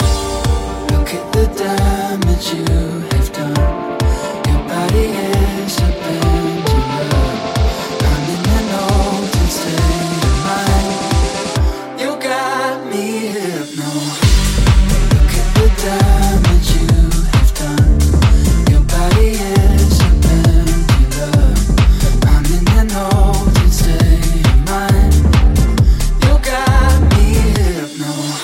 Genere: pop,deep,remix